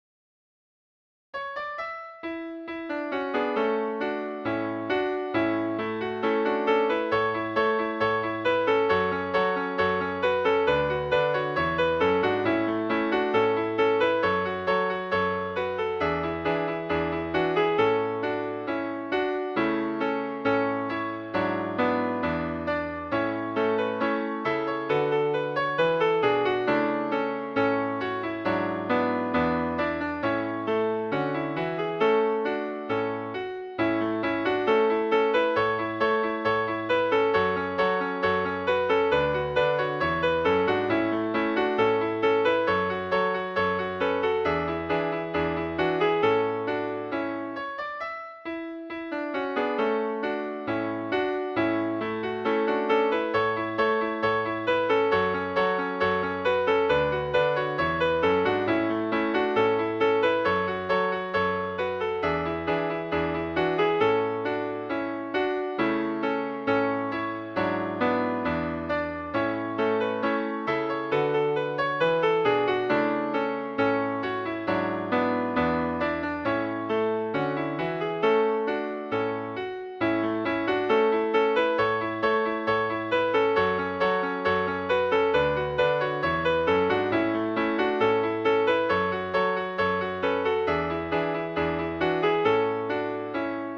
gadie.mid.ogg